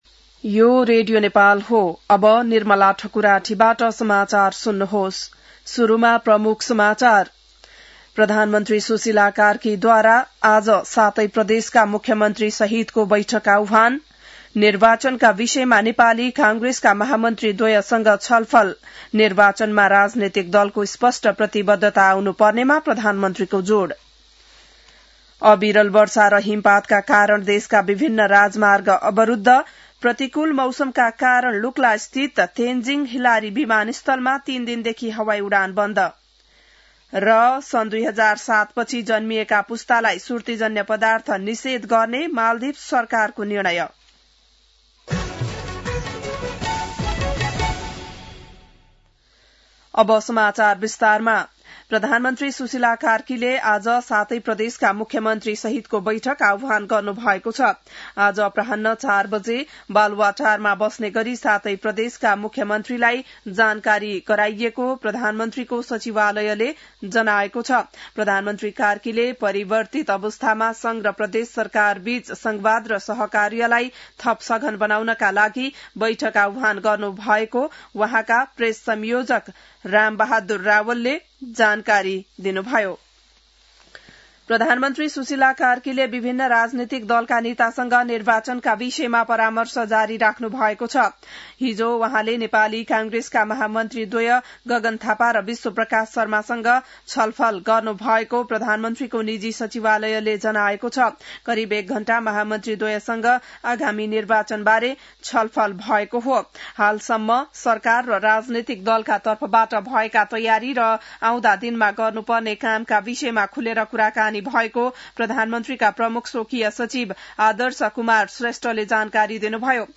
बिहान ९ बजेको नेपाली समाचार : २५ कार्तिक , २०८२